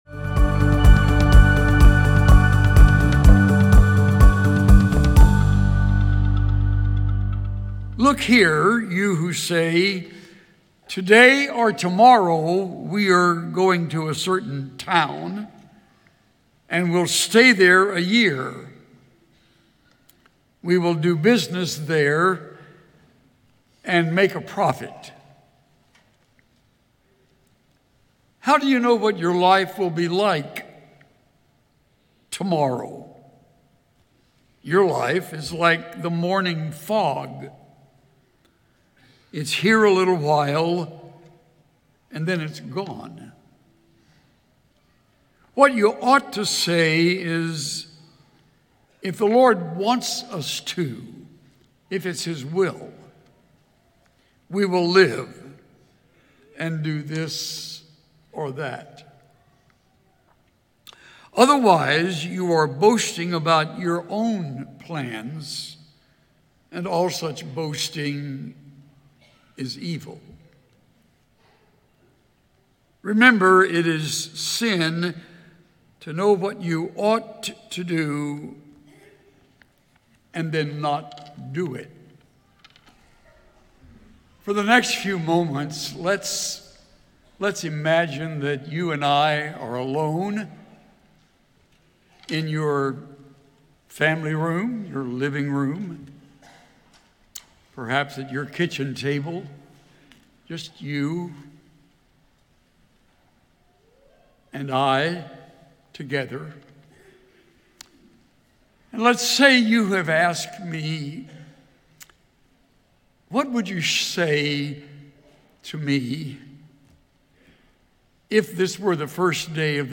Begin the new year with songs of praise to our glorious God and King. Join our whole church family as we remember our purpose and celebrate our hope in Christ.